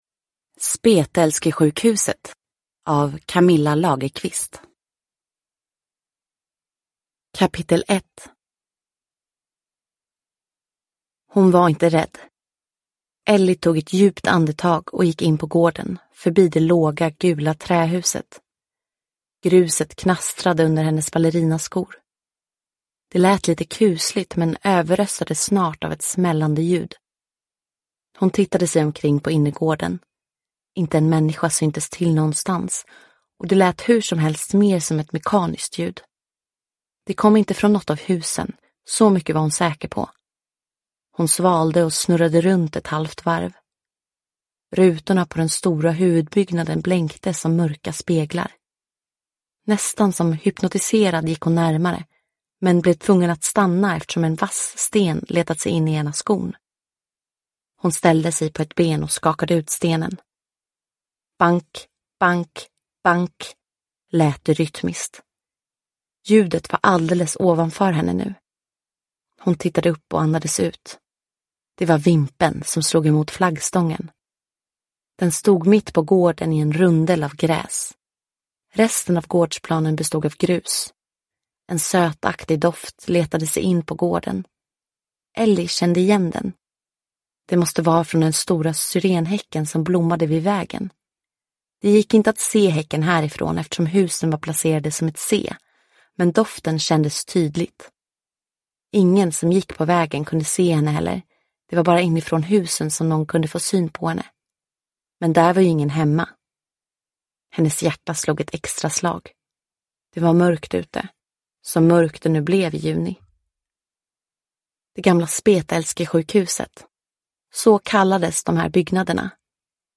Spetälskesjukhuset – Ljudbok